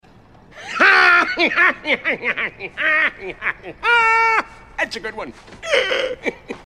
Grinch laughing
Grinch-laughs.mp3